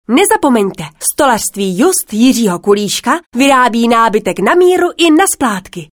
Sprecherin tschechisch (Muttersprache) für Werbung, Voice over, Imagefilm, Industriefilm etc.
Sprechprobe: Werbung (Muttersprache):
Professionell voice over artist from Czech.